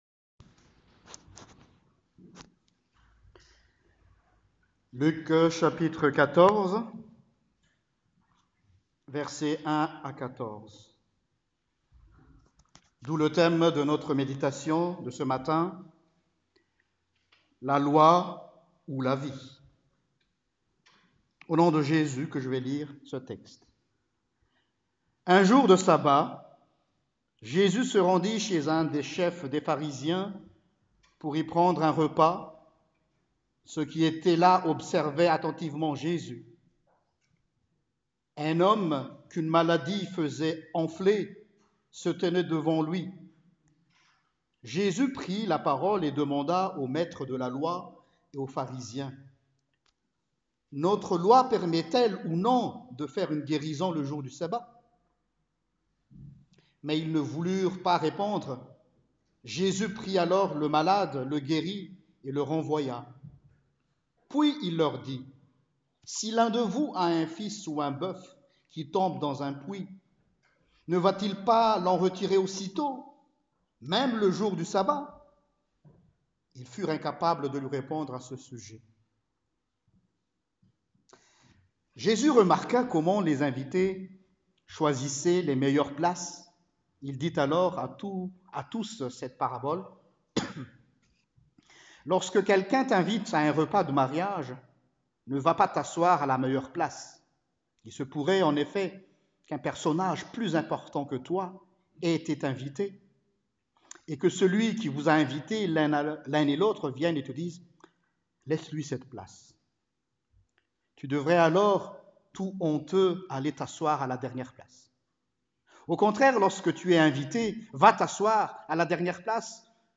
Prédication du 01 Septembre 2019